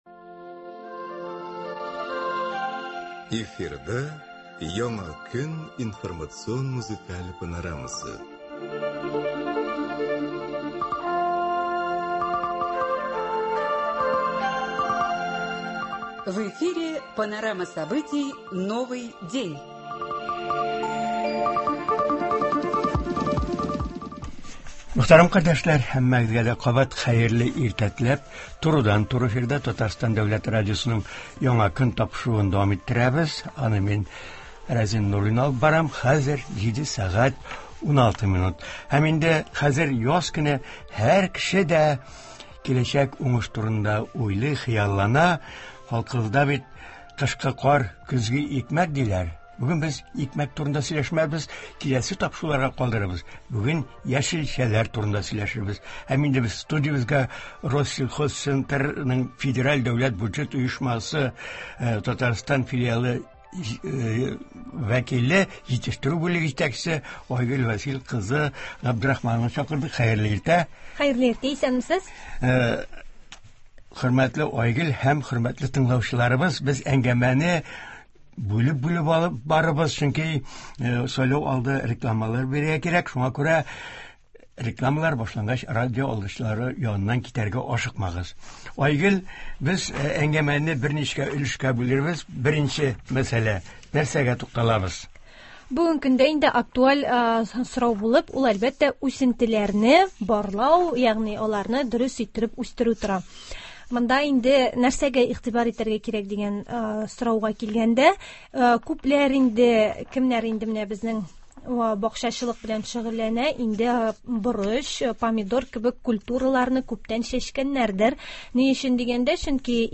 Туры эфир (13.03.24)